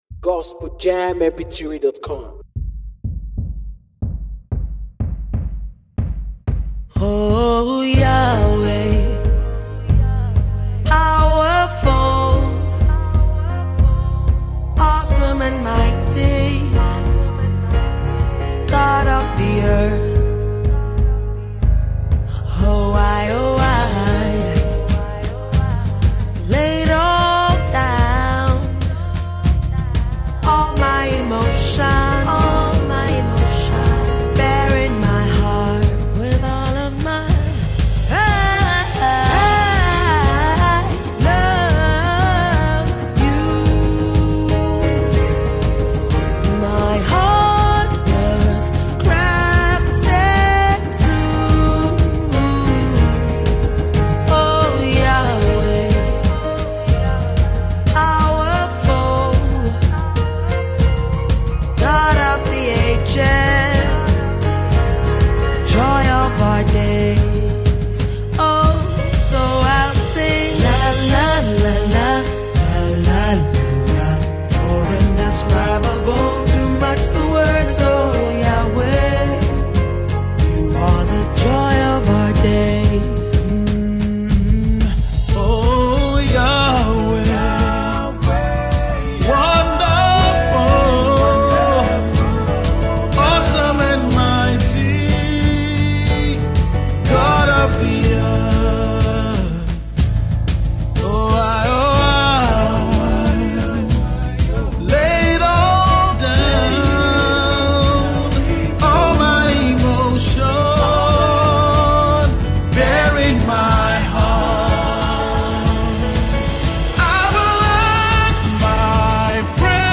melodious sound